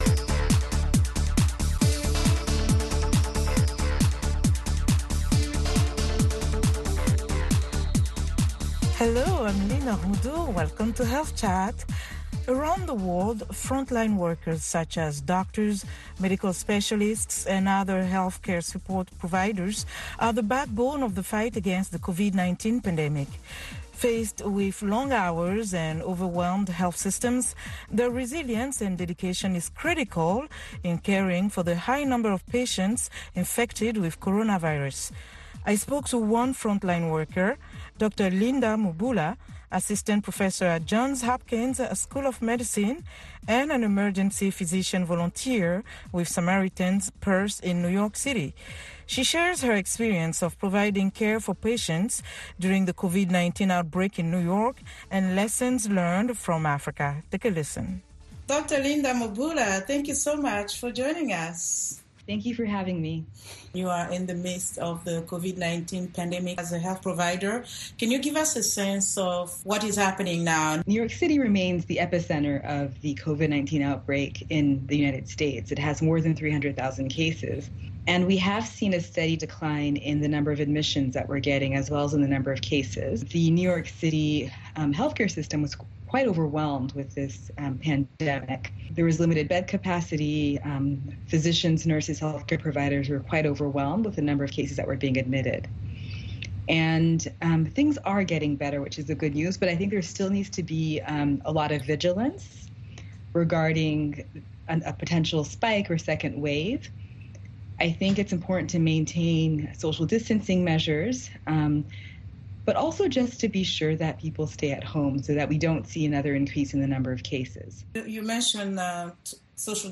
Health Chat is a live call-in program that addresses health issues of interest to Africa.